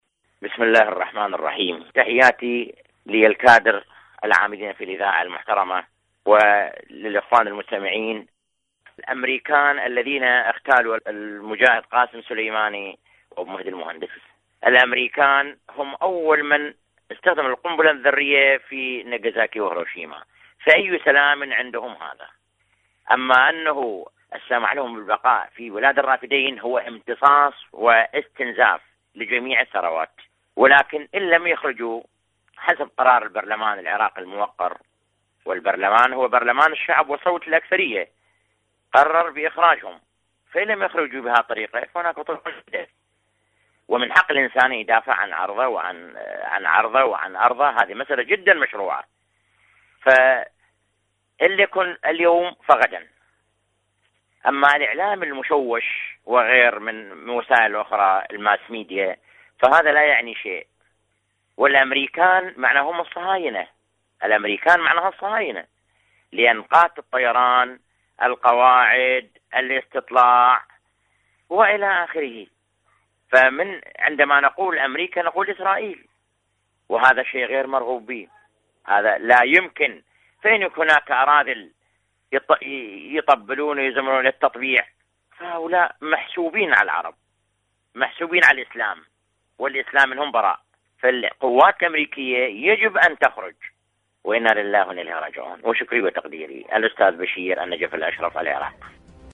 مشاركة واتساب صوتية